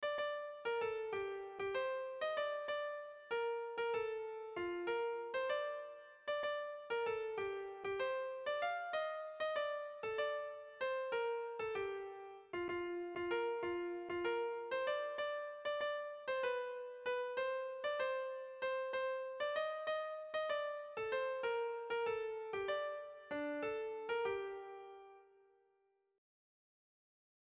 Erlijiozkoa
Bi muturreko zortziko handia, AABB errimatzen duten puntuak dituena alegia. Bestalde, bi lehenengo puntuak herriak kantatzeko eta azken biak bakarlariak kantatzeko egina dago doinua; ezpatadantzari euskaldunen urrats bizkor eta indartsuen airera sortutako melodia sarkorra. Neurrian ez dabil xuxen xuxen.
Zortziko handia (hg) / Lau puntuko handia (ip)